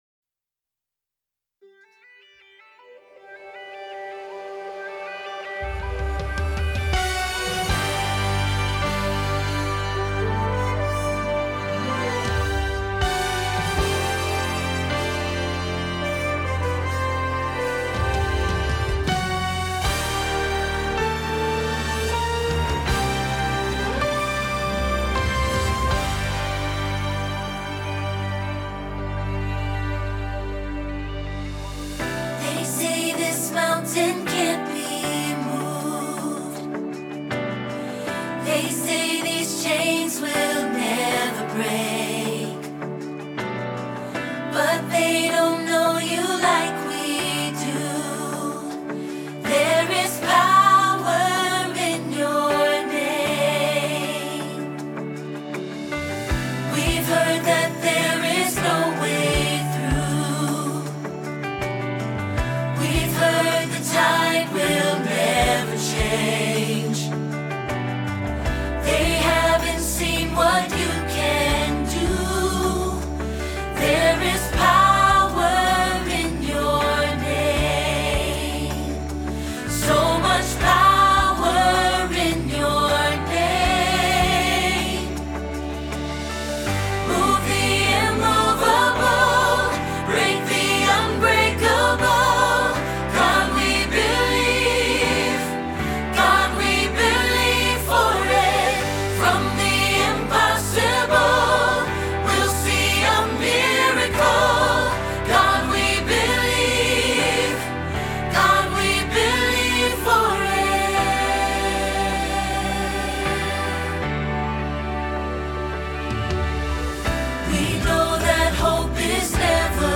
Believe for It – Soprano – Hilltop Choir